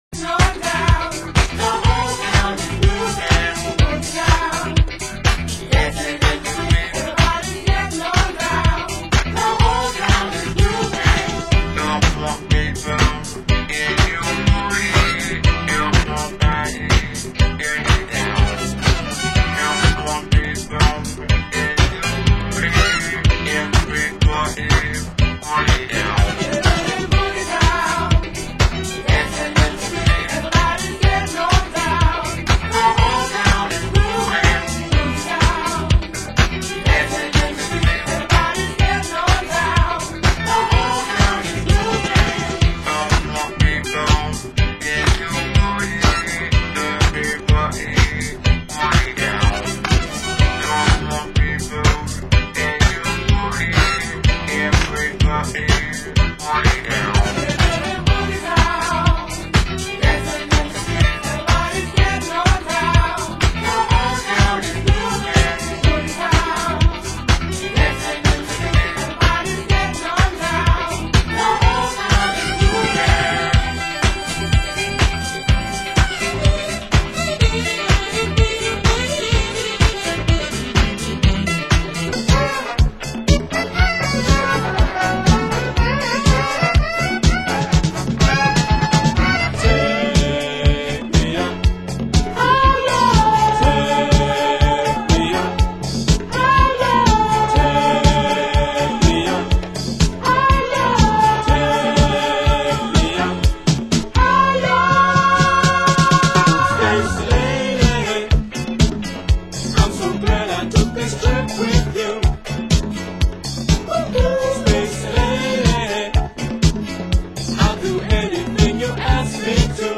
Genre Disco